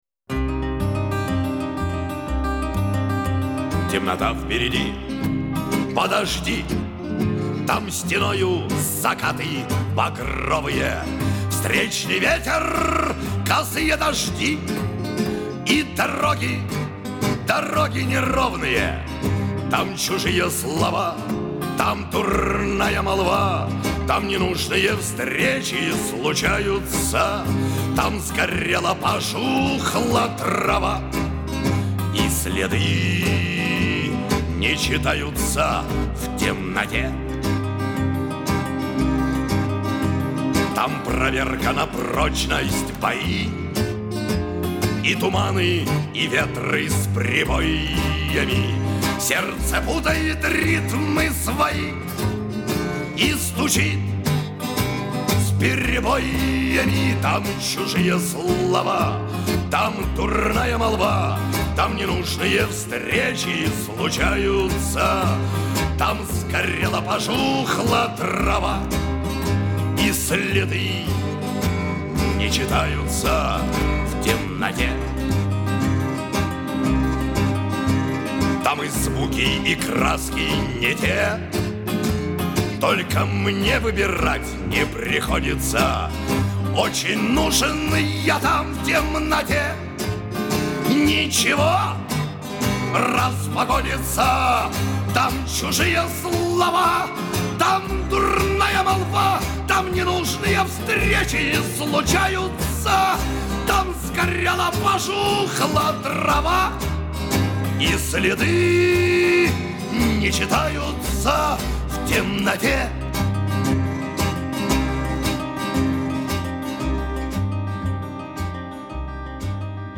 (записи Шемякина, реставрация)